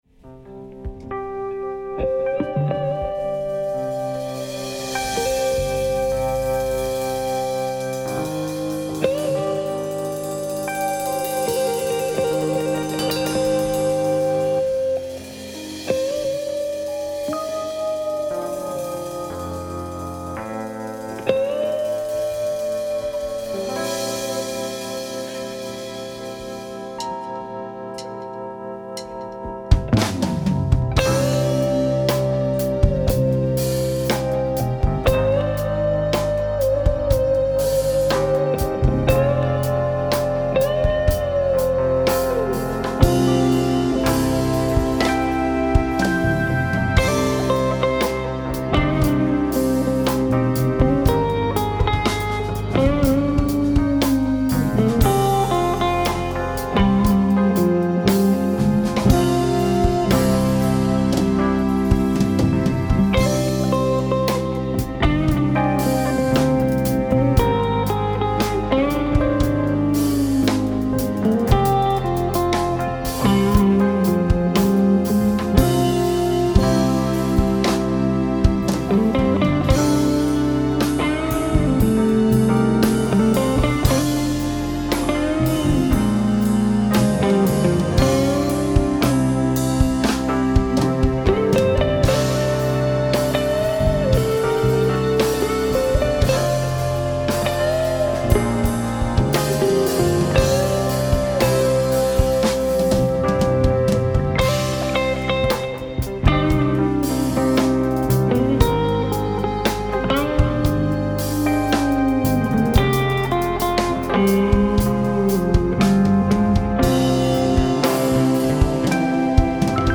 jazz fusion